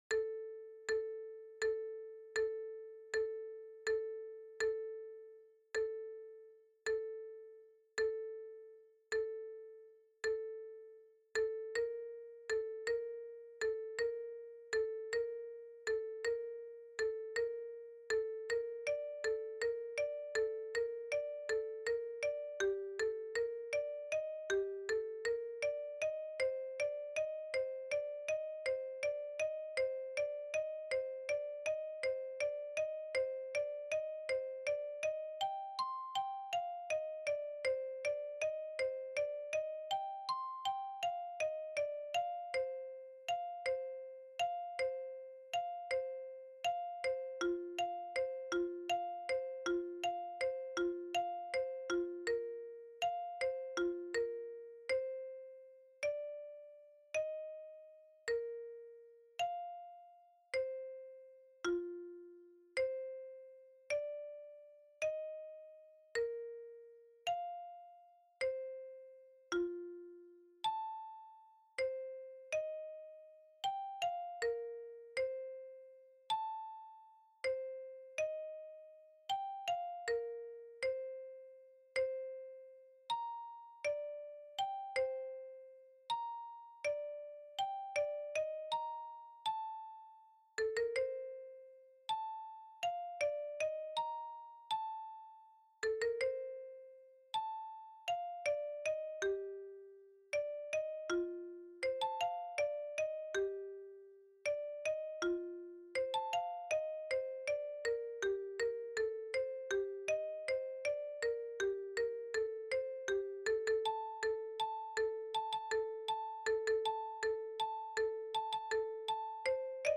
Pattern Study #2 - Stacey Bowers      melodic patterns at
80 bpm            melodic patterns at
PatternStudy2_patterns_80bpm.mp3